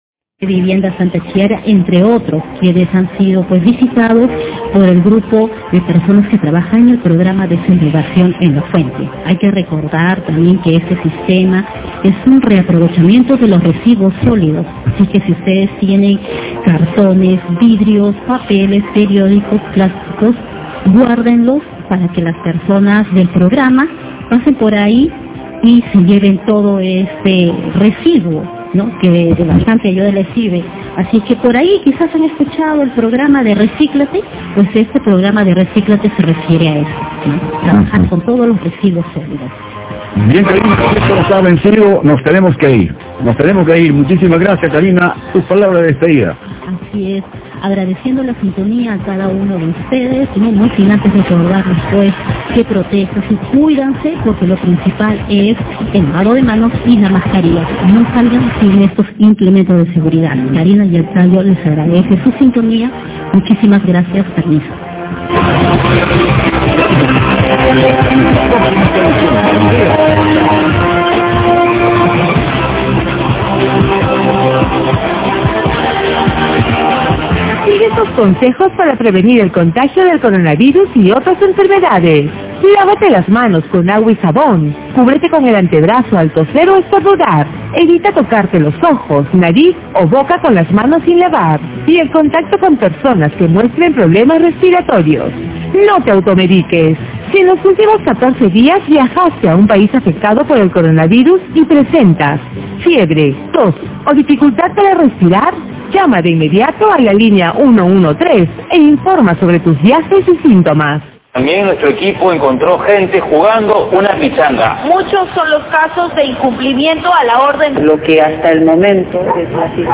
Desde un señor llorando por haber ido a jugar futbol hasta un anuncio de un programa de “música y noticias”, he aquí un audio de Andina FM 91.5, la radio que hace que no pueda escuchar PBO (mejor así, ahora la radio de Butters está con tan mala calidad que ahora ya no se puede escuchar por aquí.